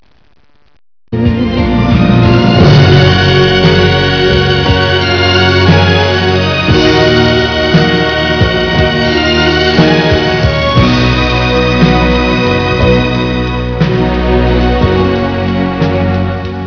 Music bridge